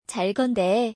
チャコンデ